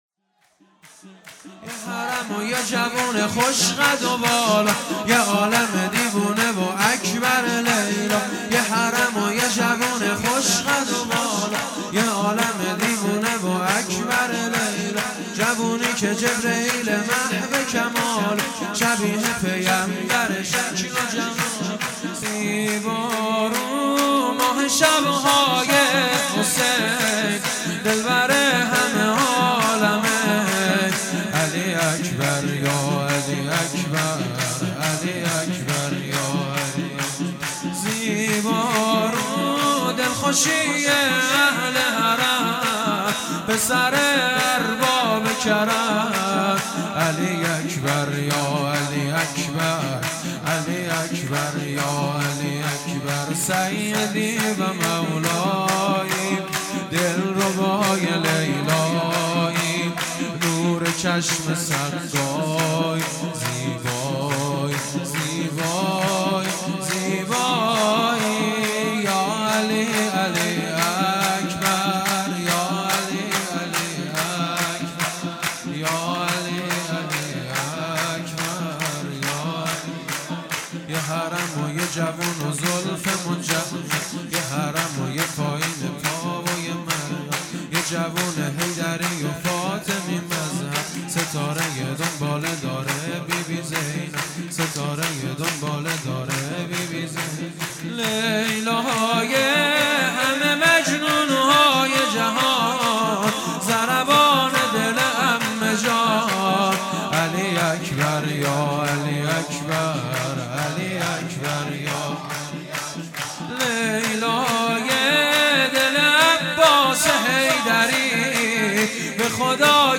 سرود
هیئت دانشجویی فاطمیون دانشگاه یزد